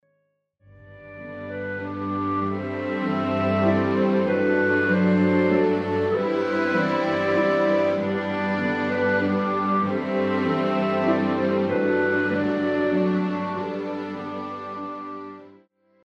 G dur　三部形式　二管の管弦楽　２分８秒
フルート・オーボエ・クラリネット・ファゴットの順に、
旋律のソロを与え、木管楽器の紹介をしています。
視聴１（クラリネットのソロ）